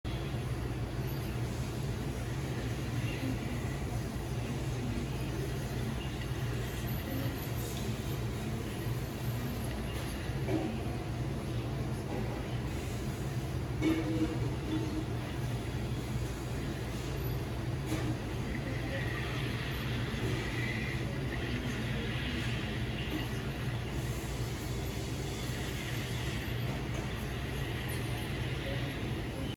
birds.ogg